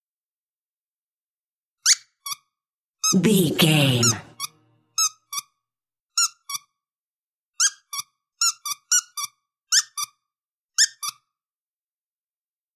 Dog toy
Sound Effects
funny
cheerful/happy